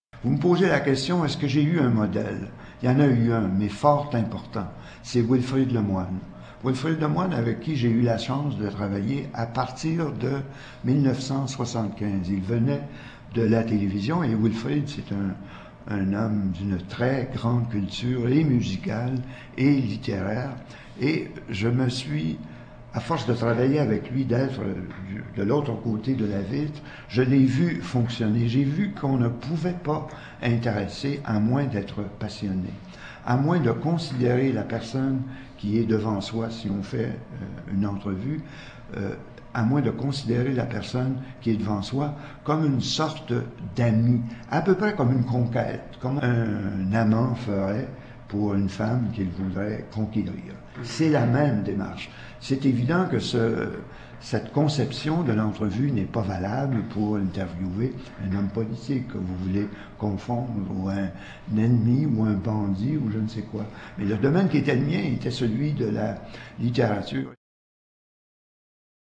Animateur radio et collectionneur (jazz)
Résumé de l'entrevue :